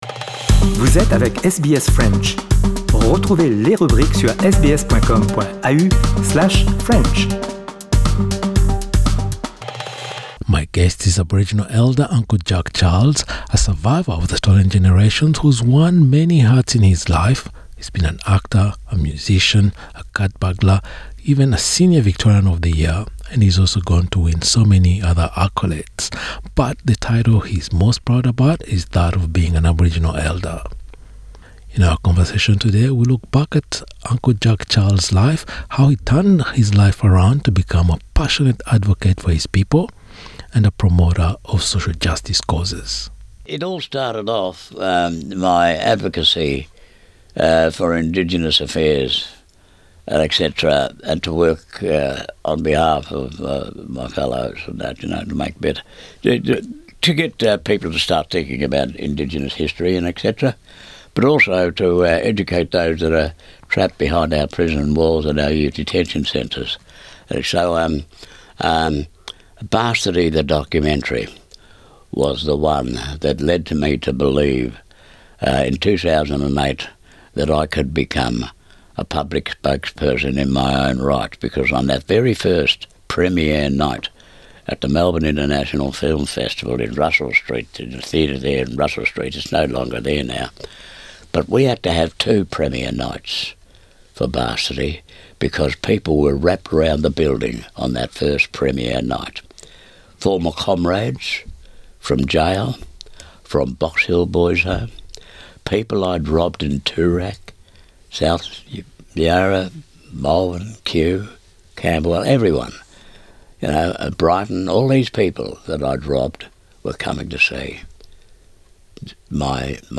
Le leader aborigène Uncle Jack Charles explique comment lui est venue sa passion pour la défense du bien-être de son peuple et de la justice sociale.